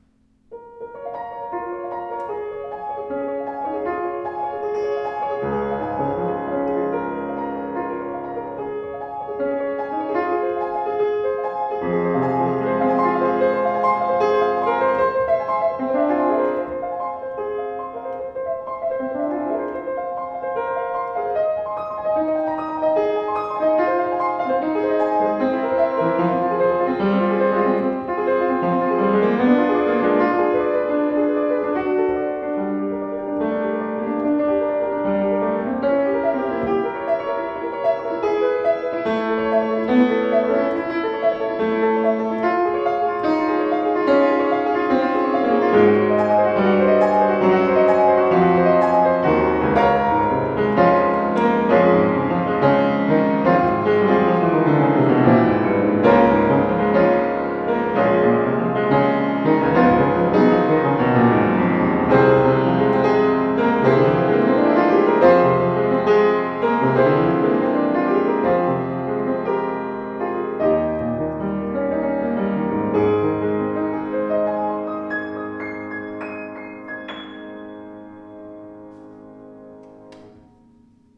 Erstaunlich voller und schöner Klang (das Modell 116 hat 118 cm Bauhöhe!) mit ausgewogenem Spielgefühl macht berührendes Musizieren möglich.
Klaviere